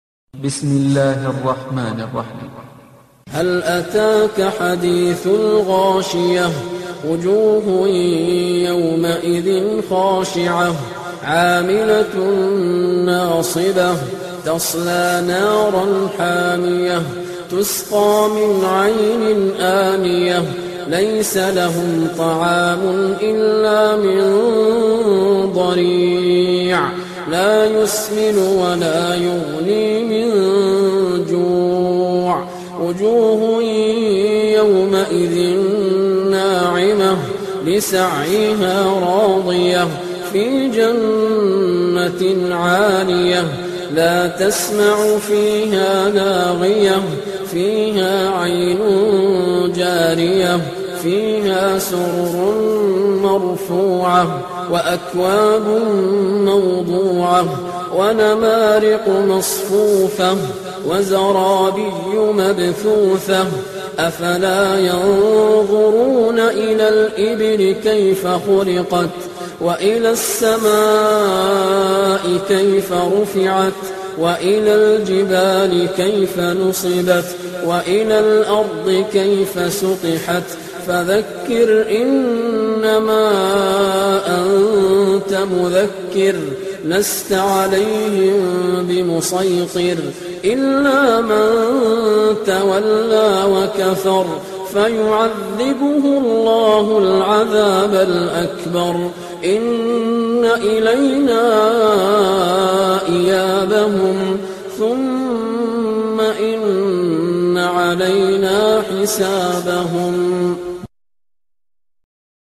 Surah Sequence تتابع السورة Download Surah حمّل السورة Reciting Murattalah Audio for 88. Surah Al-Gh�shiyah سورة الغاشية N.B *Surah Includes Al-Basmalah Reciters Sequents تتابع التلاوات Reciters Repeats تكرار التلاوات